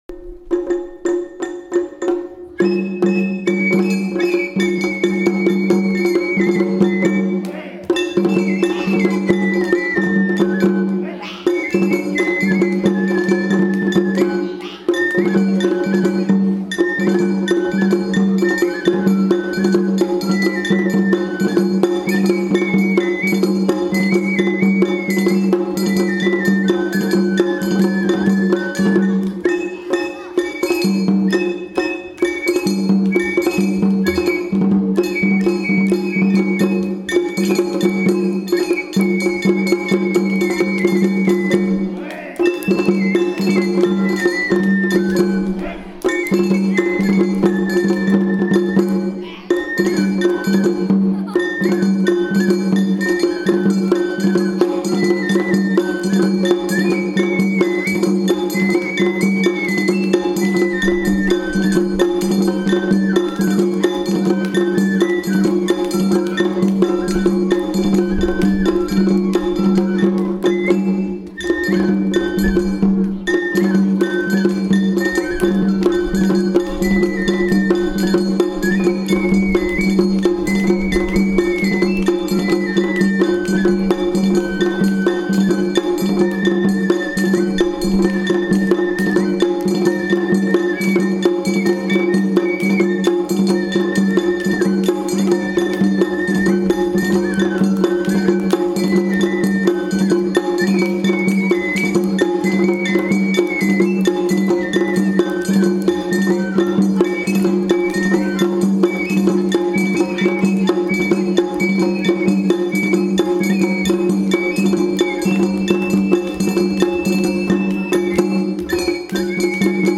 お囃子ミニ発表会
本日のロング昼休みは、野中町お囃子保存会のご協力で、体育館でミニ発表会を開催しました。お囃子の軽やかな音色が体育館内に響きました。野中町の児童も演奏に参加しました。休み時間で外遊びのできる時間にも関わらず、多くの児童が体育館に集まり、伝統的なお囃子の音に耳を澄まして聴いていました。